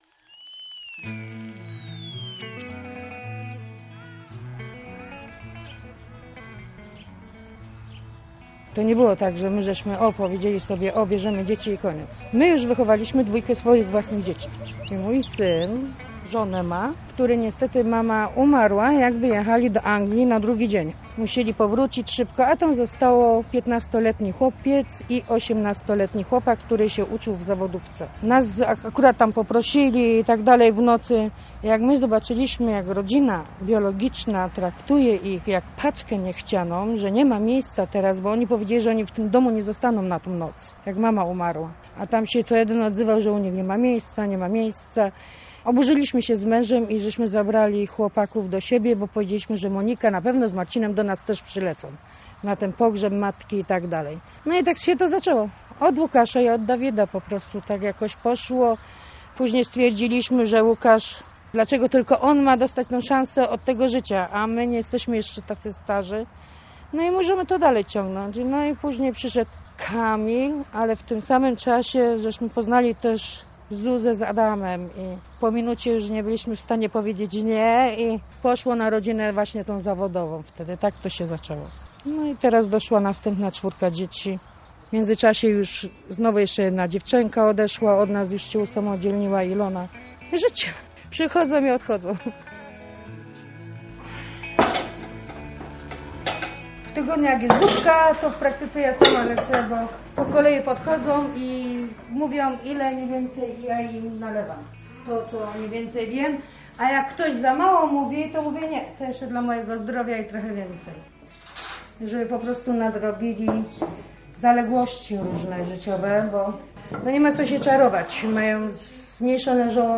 Zdecydował przypadek - reportaż